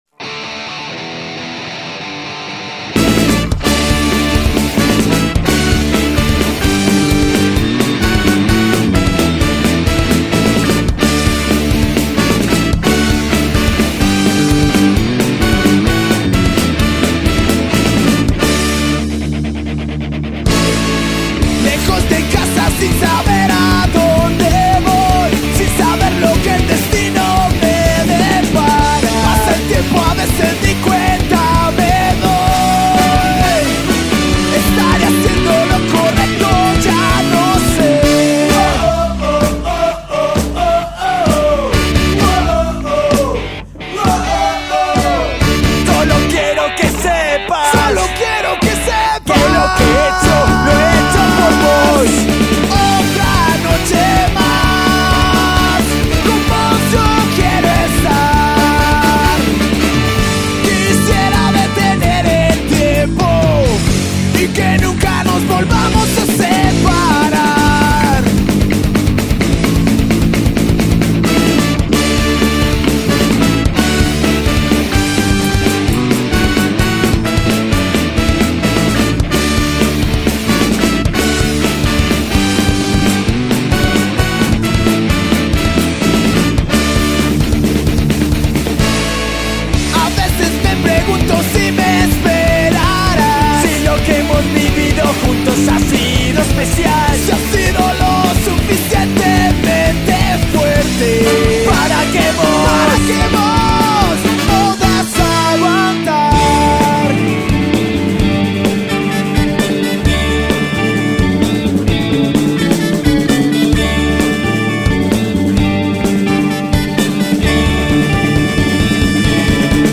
Skacore